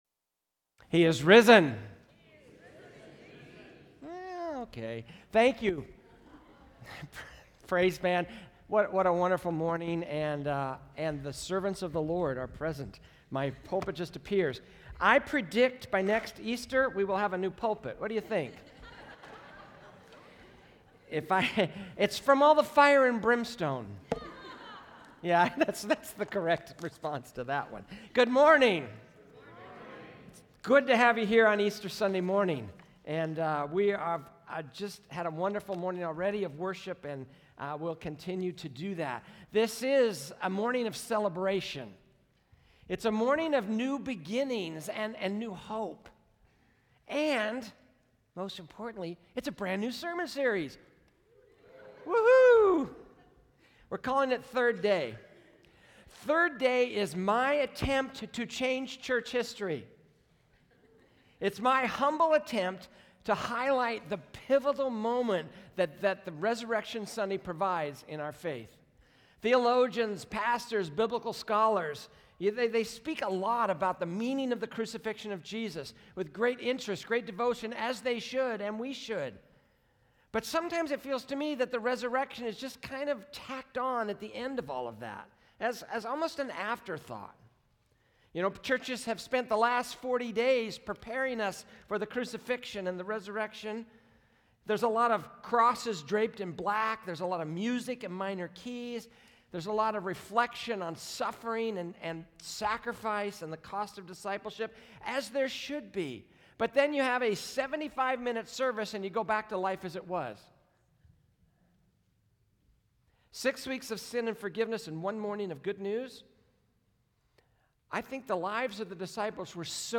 A message from the series "Third Day."